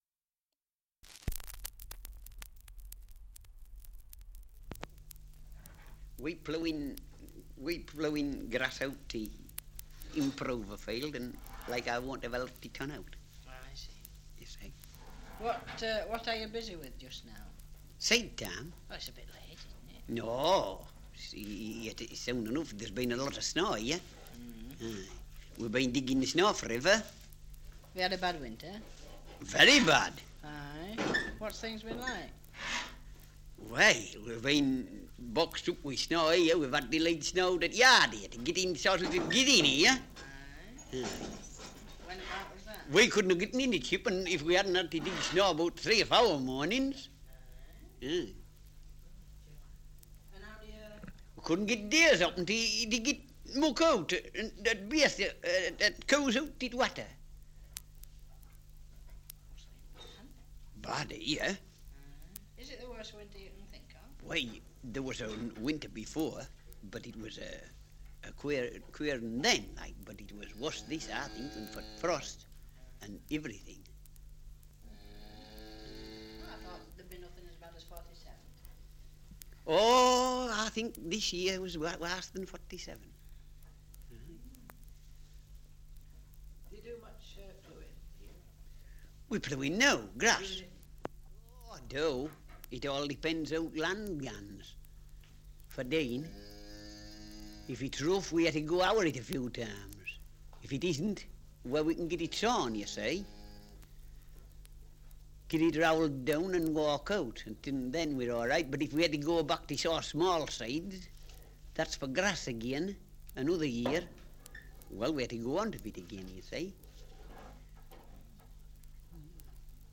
2 - Survey of English Dialects recording in Skelton, Yorkshire
78 r.p.m., cellulose nitrate on aluminium